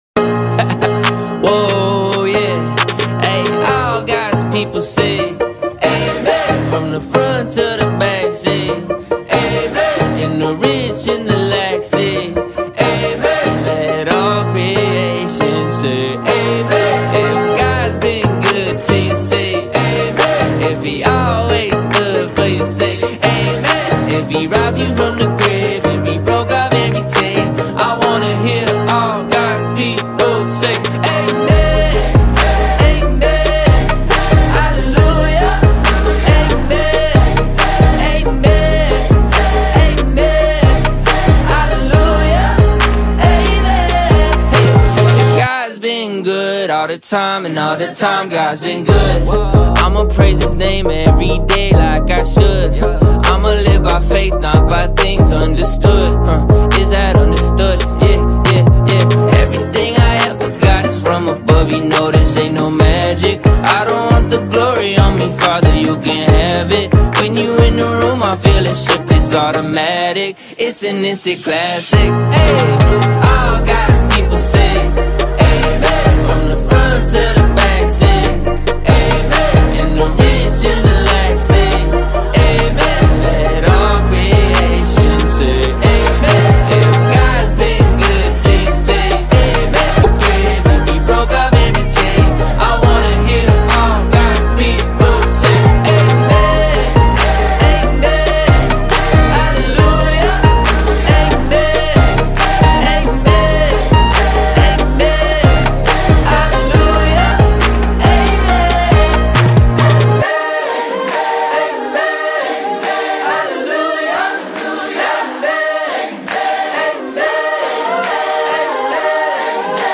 powerful worship song